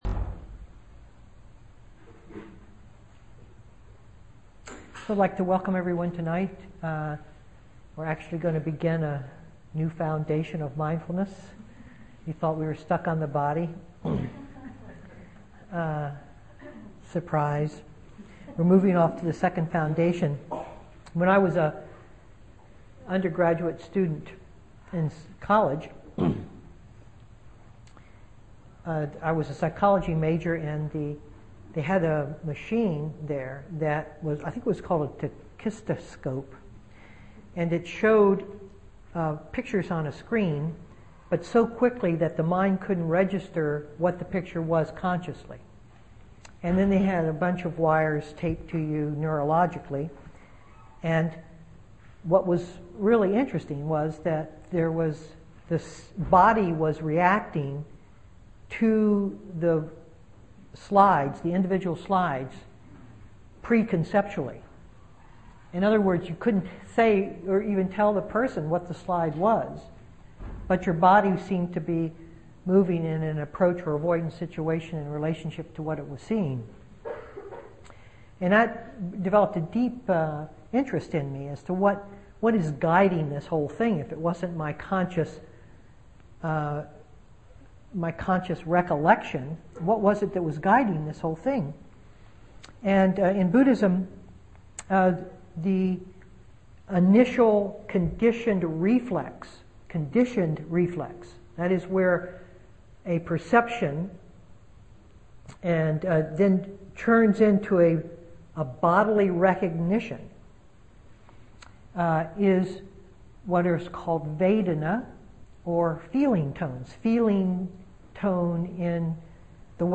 2010-09-14 Venue: Seattle Insight Meditation Center